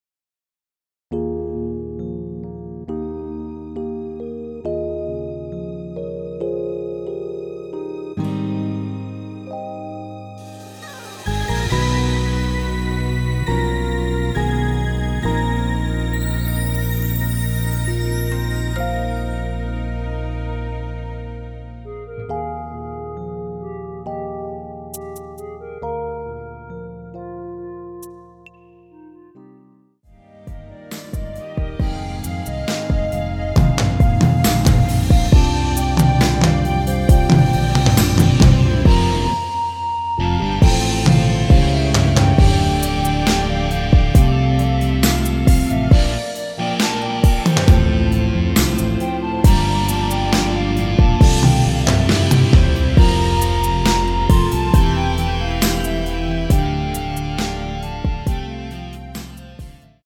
원키에서(-5)내린 멜로디 포함된 MR입니다.(미리듣기 확인)
Eb
앞부분30초, 뒷부분30초씩 편집해서 올려 드리고 있습니다.